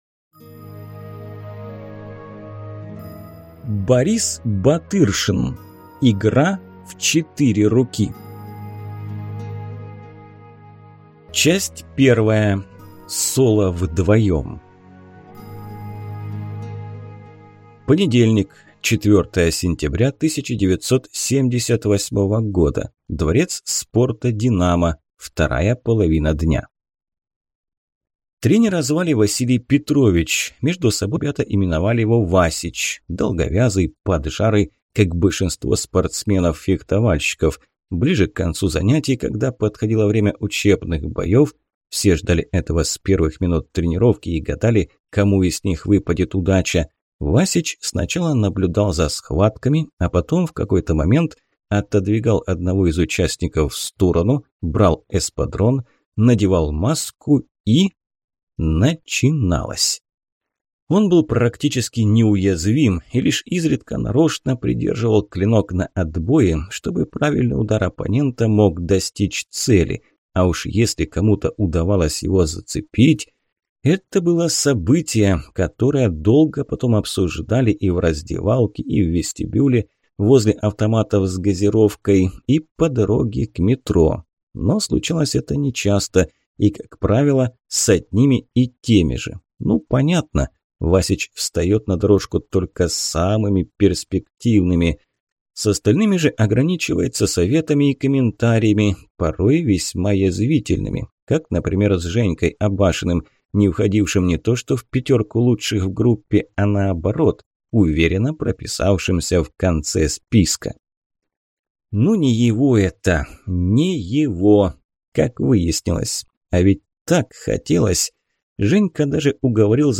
Аудиокнига Игра в четыре руки | Библиотека аудиокниг